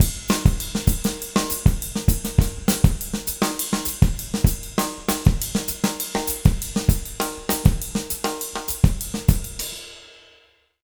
100SONGO03-R.wav